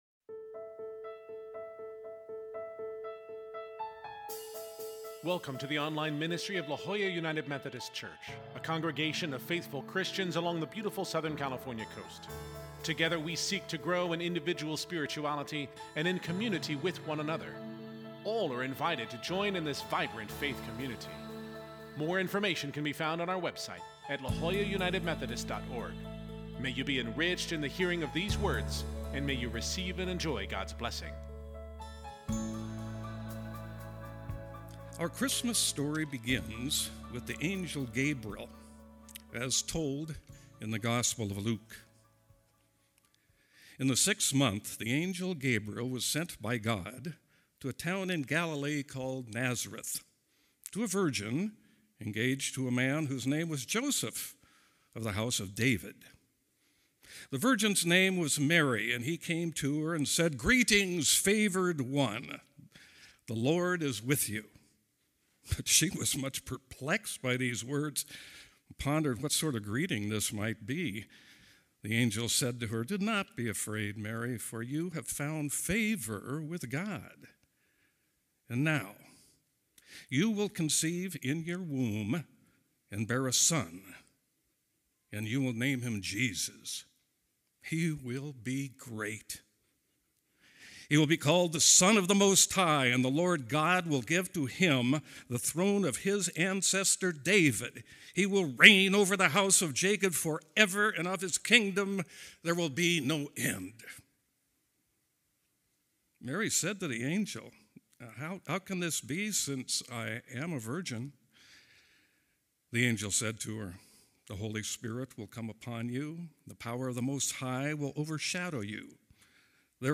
Our 7:00 pm Christmas Eve Service is open to persons of all ages and includes music, scripture, an inspiring message, and more. This service also concludes with Christmas carols and candles.